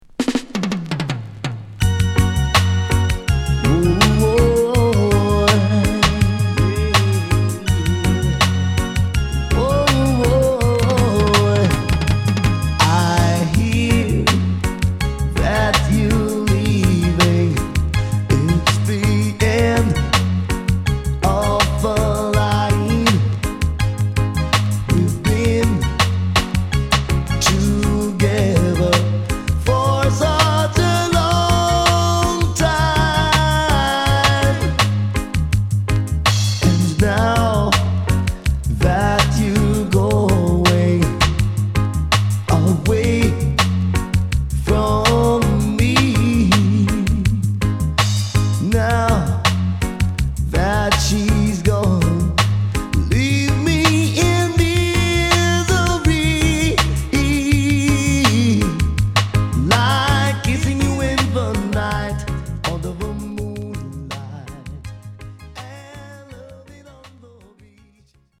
HOME > Back Order [DANCEHALL LP]
SIDE A:盤質は少しチリノイズ入りますが良好です。